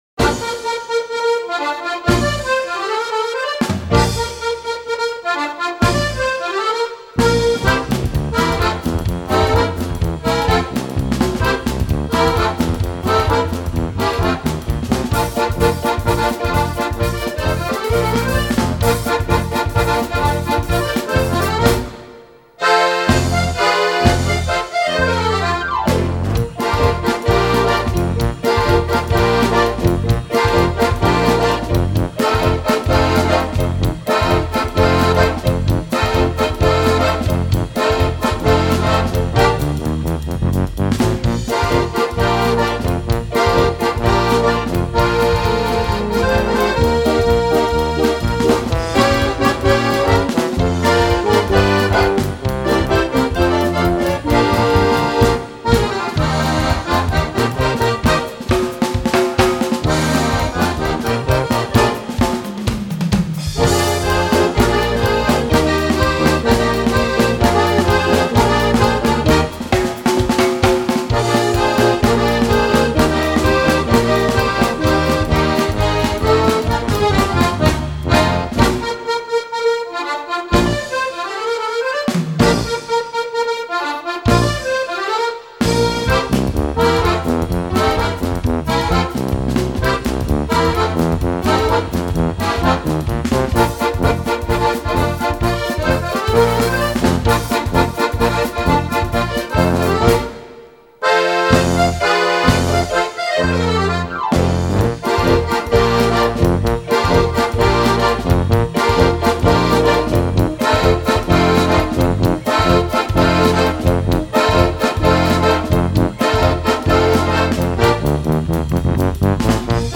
So arbeitete man von Anfang an mit Schlagzeug und E-Bass.